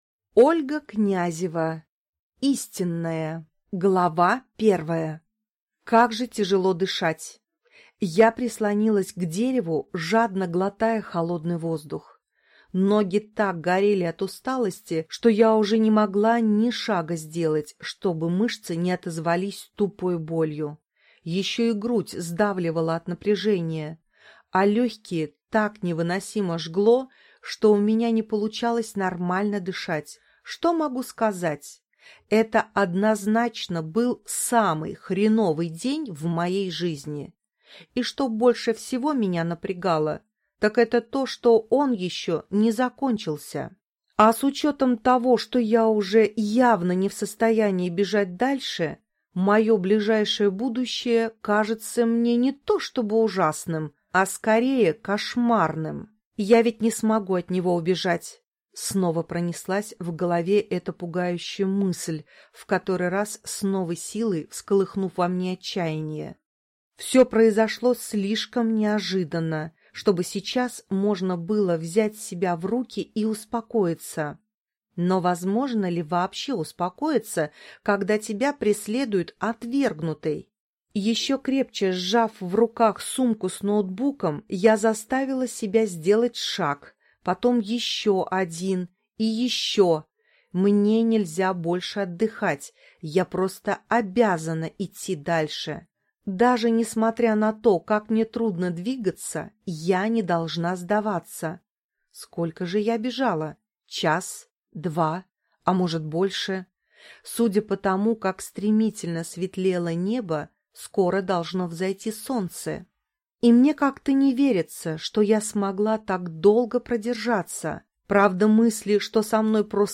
Aудиокнига
Прослушать и бесплатно скачать фрагмент аудиокниги